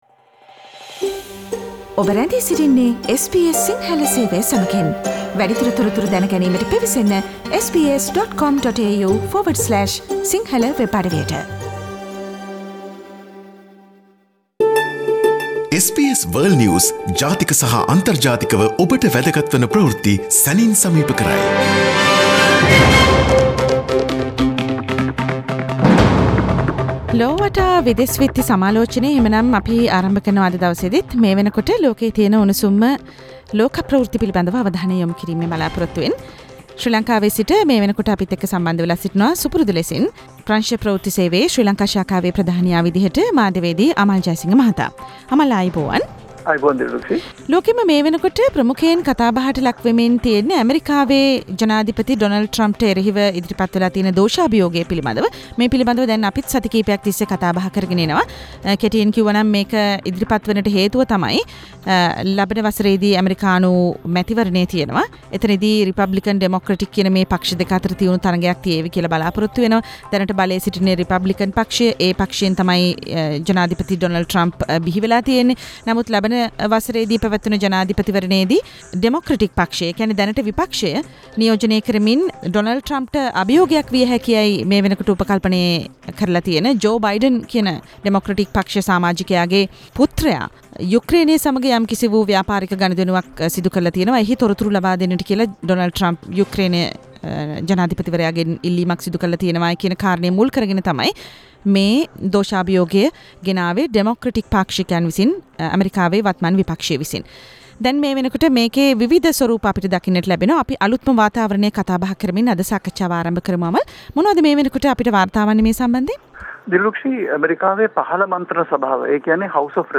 විදෙස් විත්ති සමාලෝචනය - සෑම සතියකම SBS සිංහල ගුවන් විදුලියේ සිකුරාදා වැඩසටහනින්